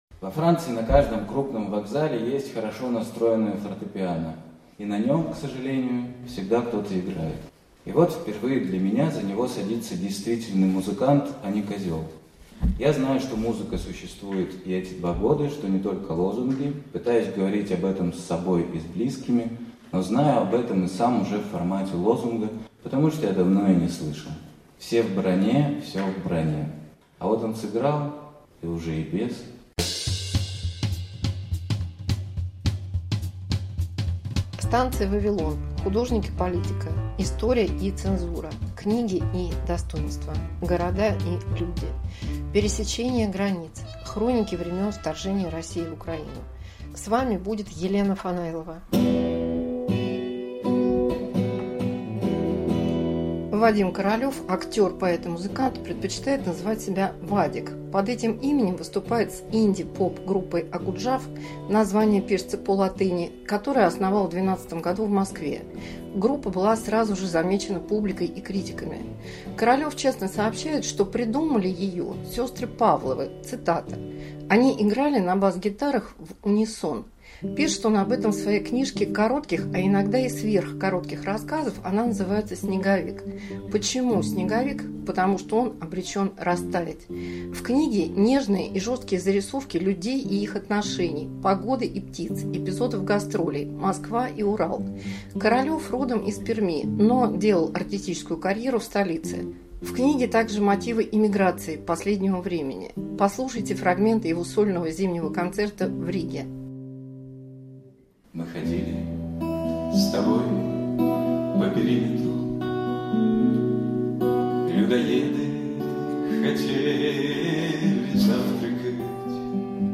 Предновогодний концерт